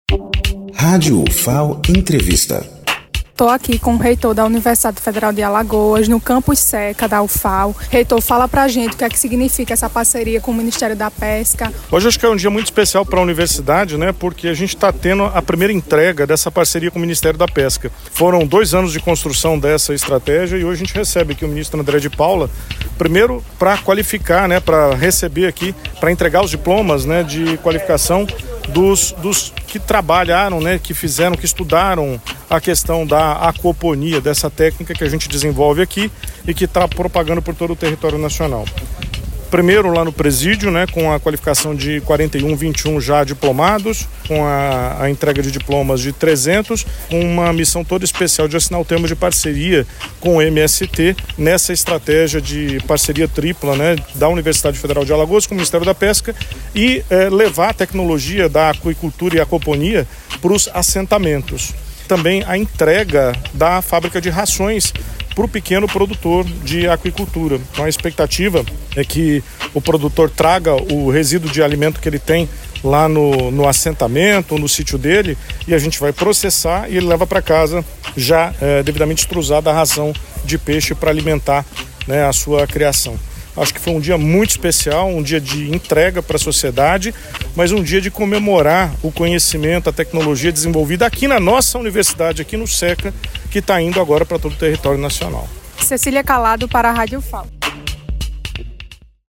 Reitor Josealdo Tonholo fala sobre a vinda do Ministro André de Paula à Ufal